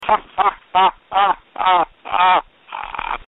Tags: funny comedy podcast radio disgusting